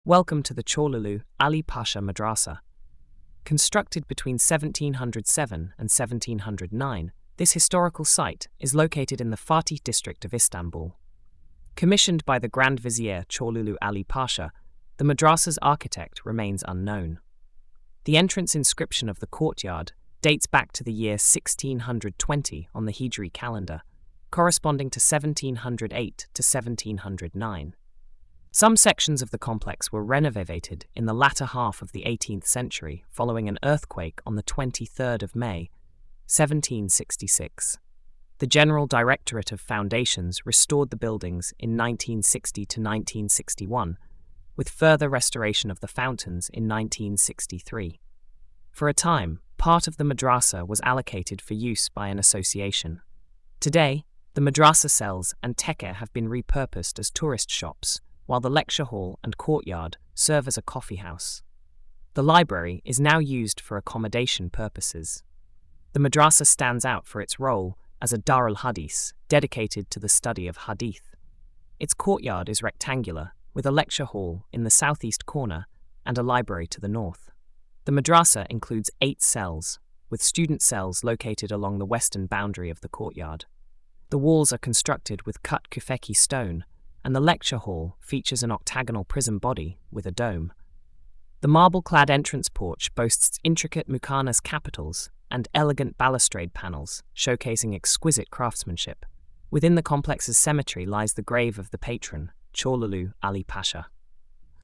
Sesli Anlatım: